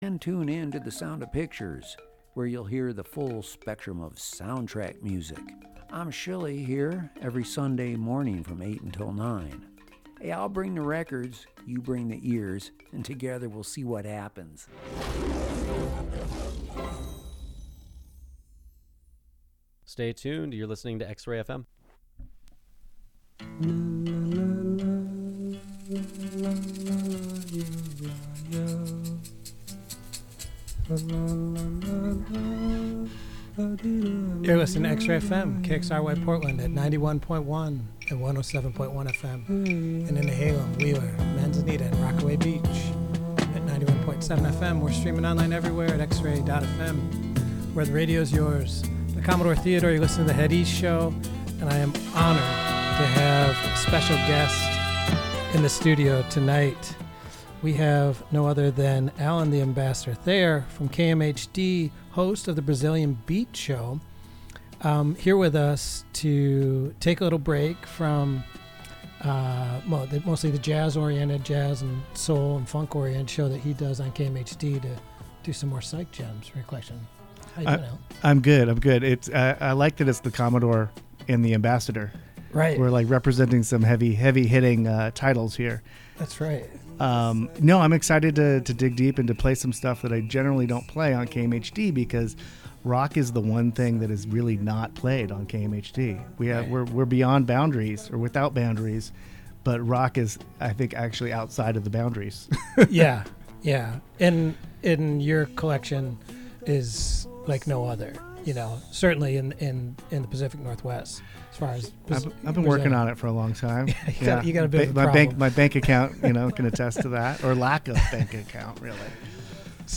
Drawing broadly from world interpretations of rock, soul, and punk, HEAD EAST brings you thee grooves, beats, riffs, and bleeps that matter to defining heaviness. Shaking up genres from the mid-60s thru the 80s with occasional newer jams, let's head east.